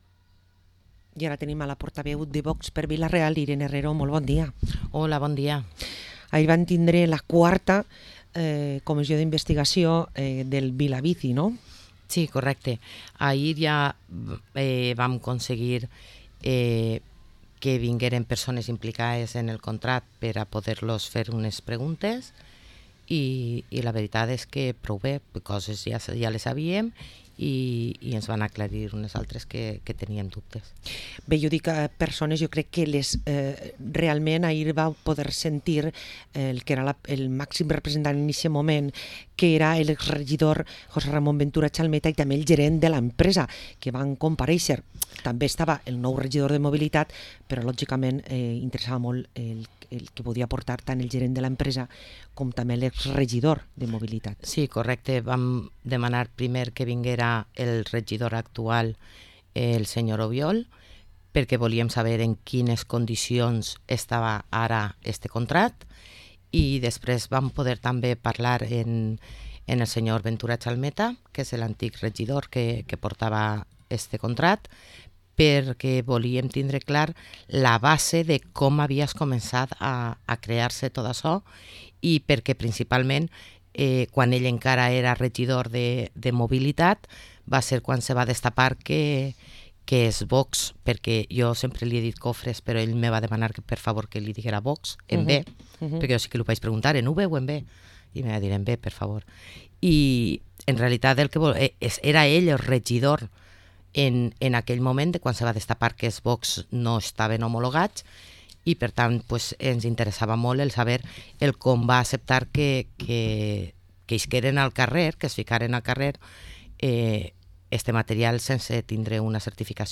Parlem amb Irene Herrero, portaveu i regidora de VOX a l´Ajuntament de Vila-real